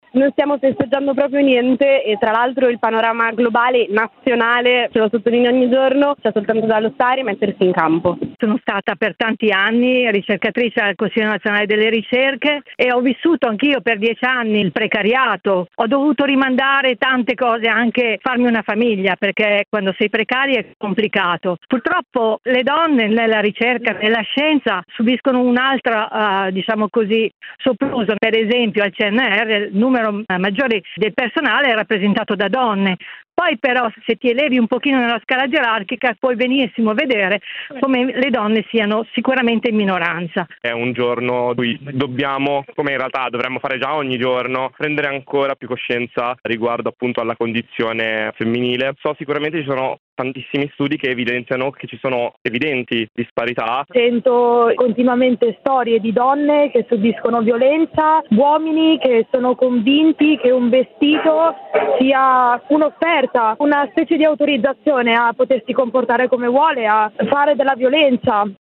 VOCI-1930-corteo-milano.mp3